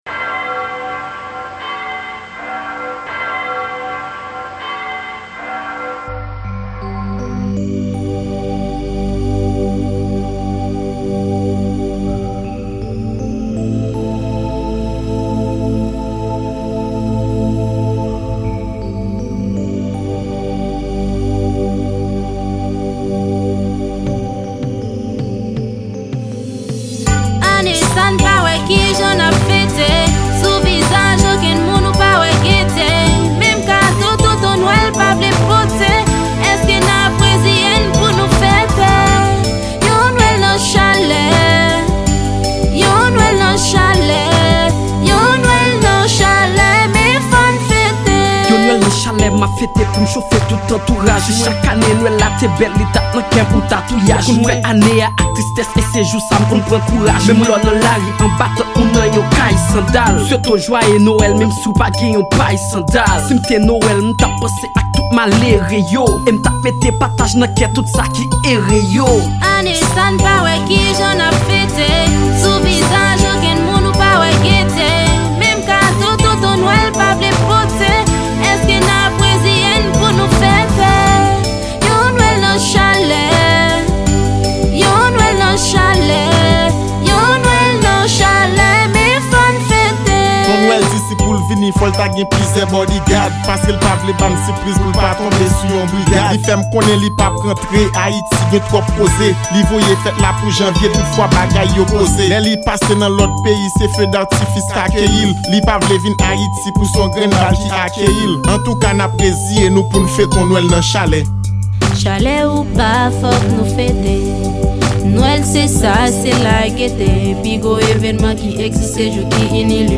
Genre: NOEL.